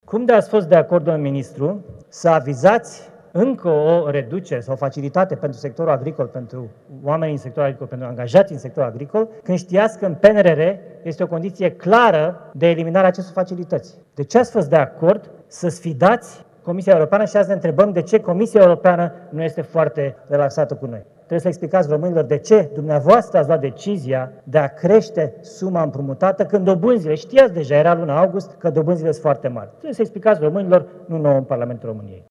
Ministrul Finanțelor, chemat la explicații în Senat.
Adrian Câciu nu a scăpat nici de criticile liberalilor. Florin Cîțu, fost ministru de Finanțe și premier, l-a acuzat că a îndatorat populația prin politicile aplicate: